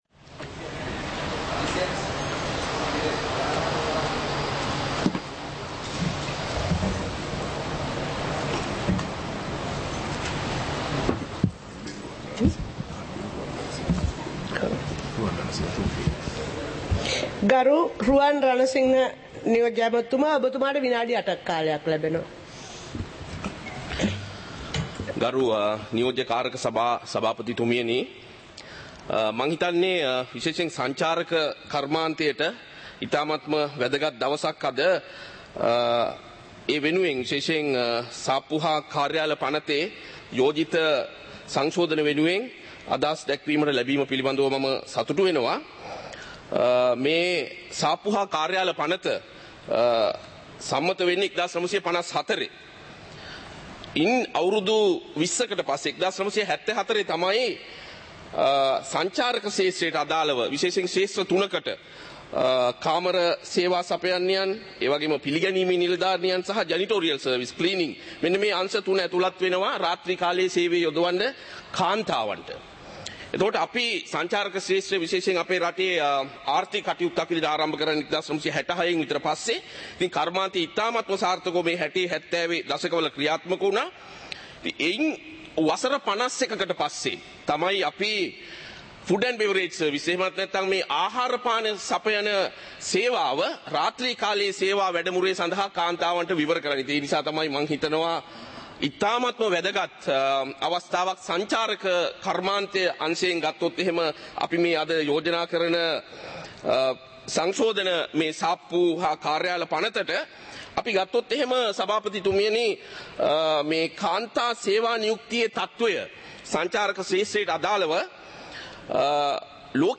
சபை நடவடிக்கைமுறை (2026-01-09)